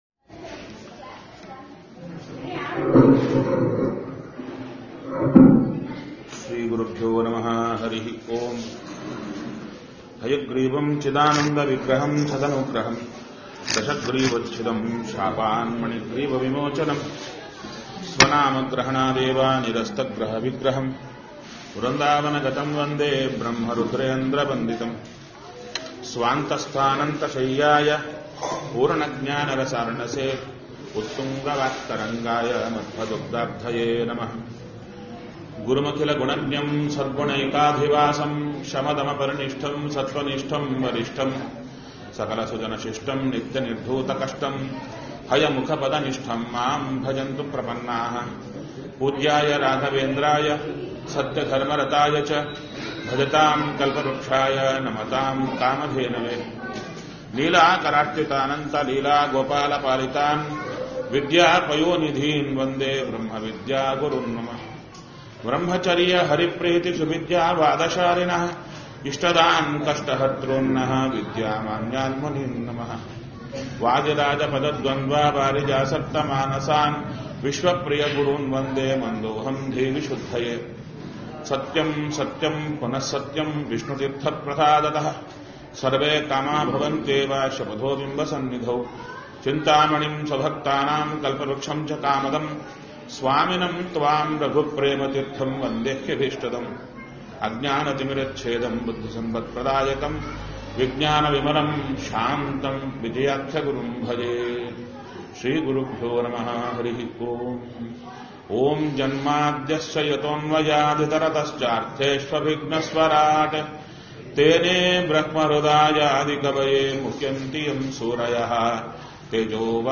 Go Home Pravachana Bhagavata Bhagavatapushkara Bhagavatha Pushkara 14.M4a Bhagavatha Pushkara 14.M4a